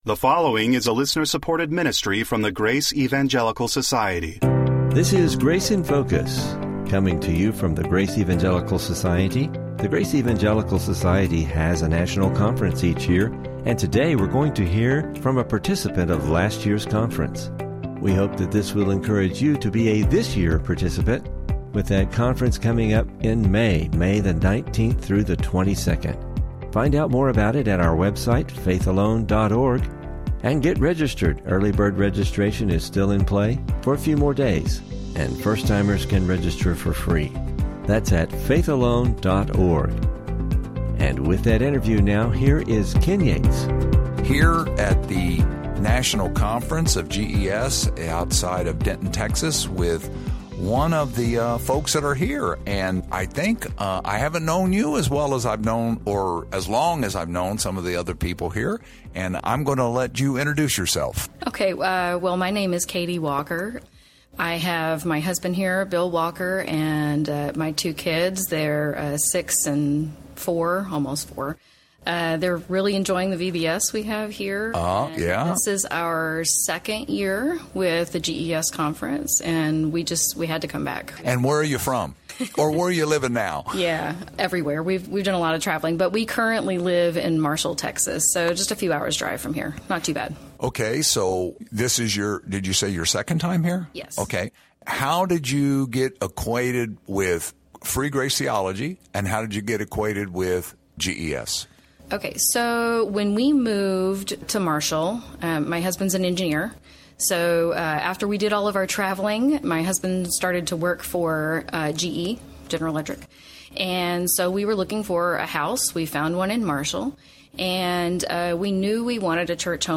Interview – GES National Conference 2024 Attendees